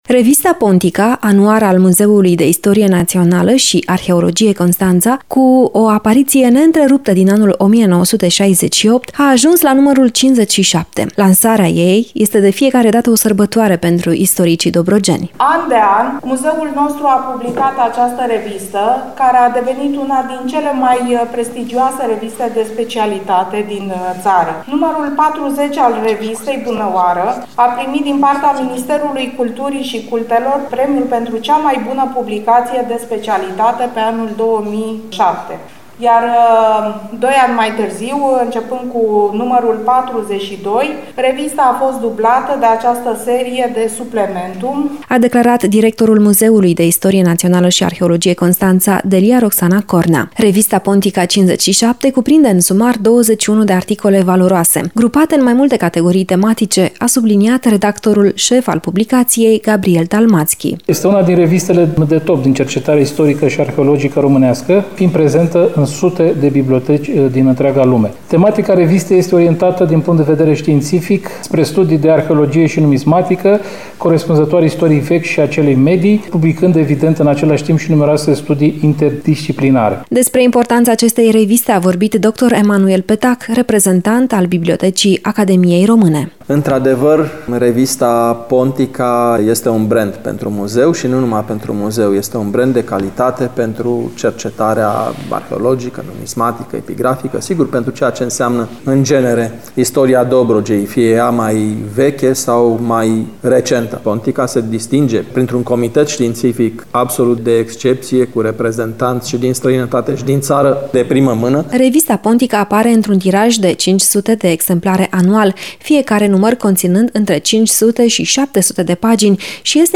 AUDIO | La Muzeul de Istorie Națională și Arheologie din Constanța a avut loc o dublă lansare de carte